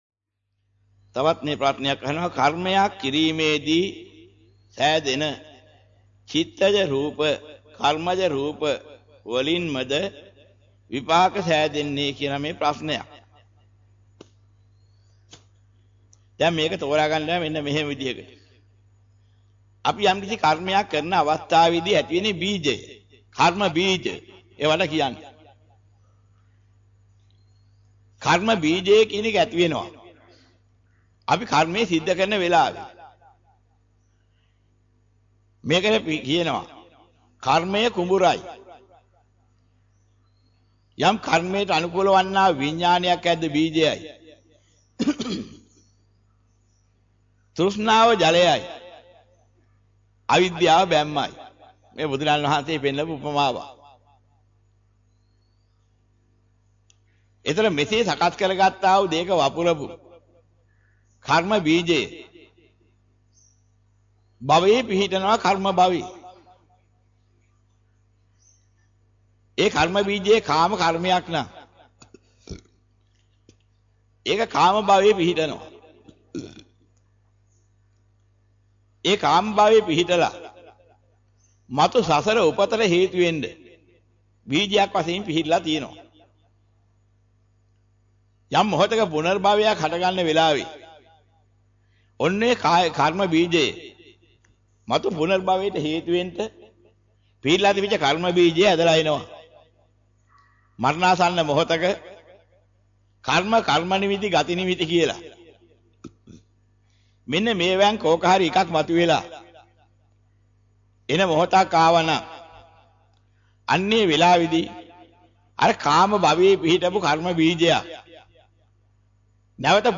වෙනත් බ්‍රව්සරයක් භාවිතා කරන්නැයි යෝජනා කර සිටිමු 07:39 10 fast_rewind 10 fast_forward share බෙදාගන්න මෙම දේශනය පසුව සවන් දීමට අවැසි නම් මෙතැනින් බාගත කරන්න  (4 MB)